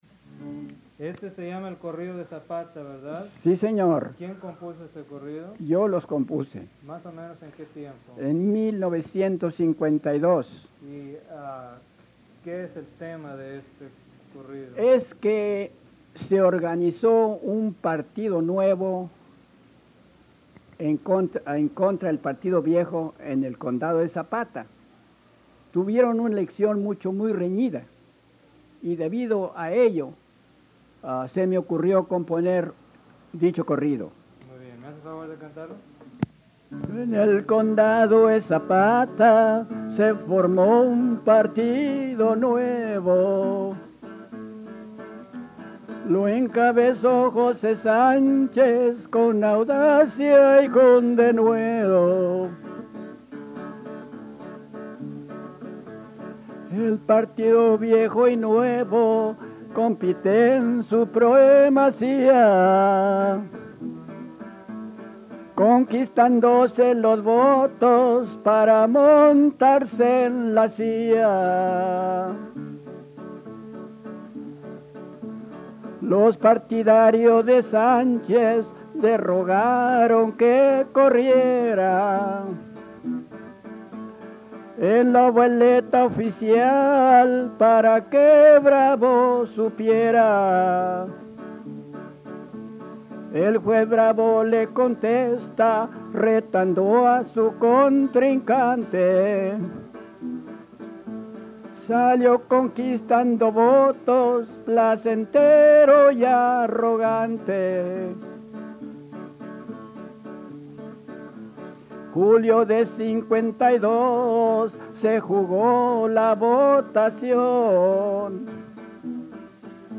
In this post, I dive deeper into the genre of corridos (narrative ballads) as they are represented in Parédes’s collection.
The practice of singing historical corridors appears to be alive and well in 1950s south Texas, which is when and where Paredes conducted the bulk of his field recordings.